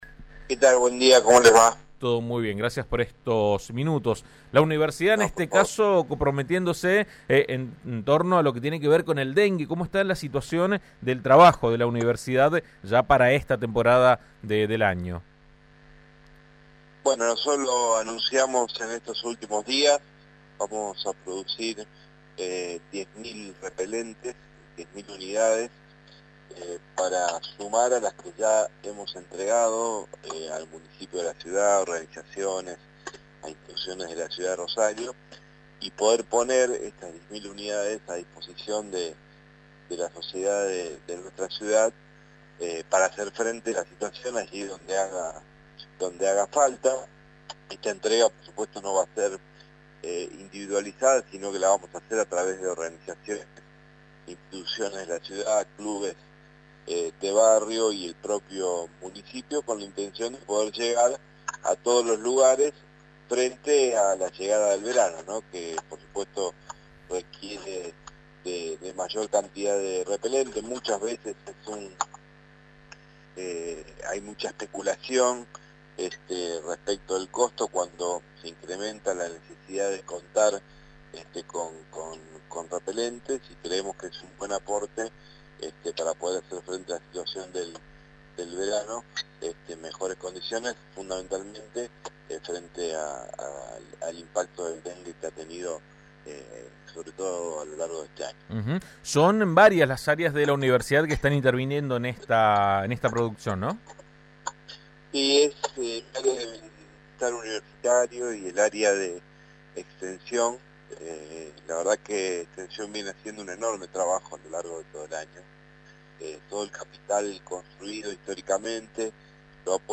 El rector Franco Bartolacci dialogó al respecto con AM 1330.